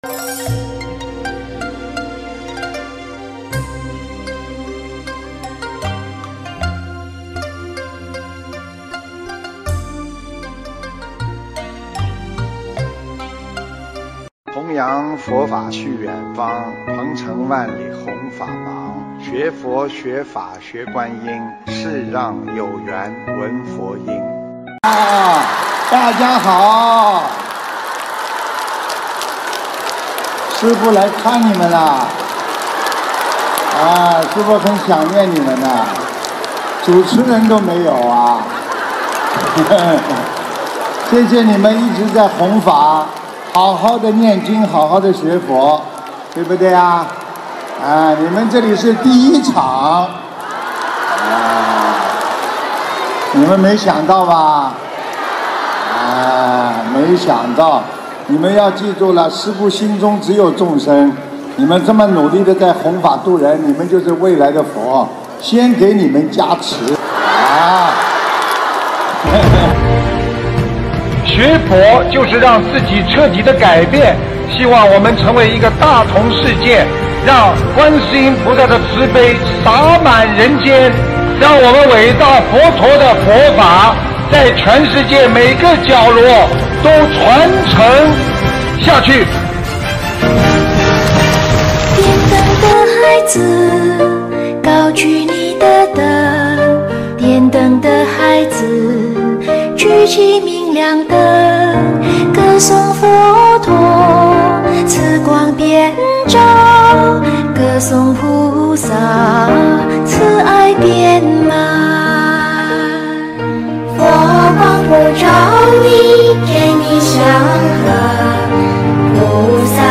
音频：德国斯图加特慈心素食交流分享会花絮！2023年06月06日